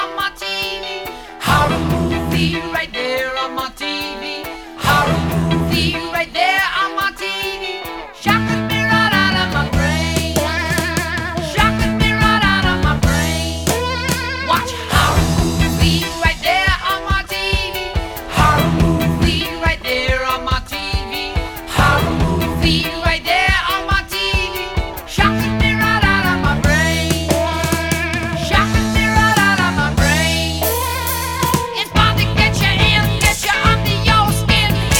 # Поп